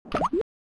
sound_drop_enemy.wav